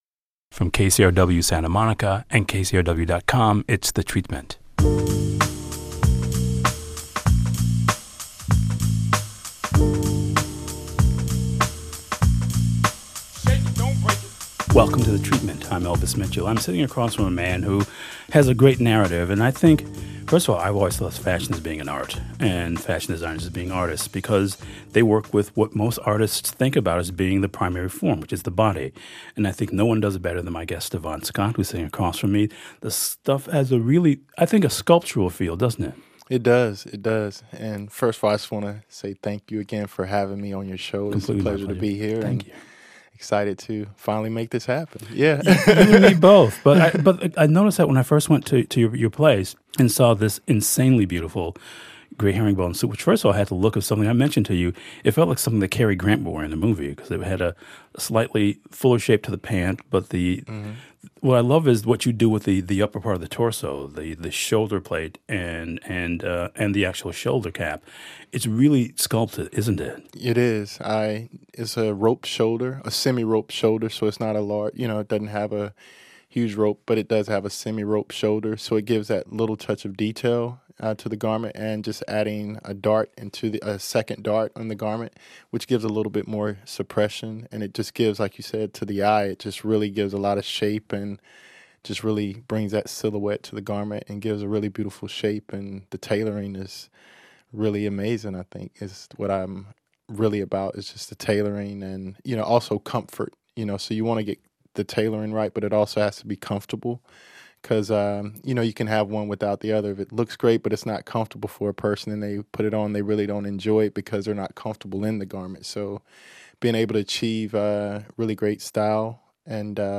Elvis Mitchell host of KCRW’s The Treatment